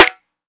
SNARE.5.NEPT.wav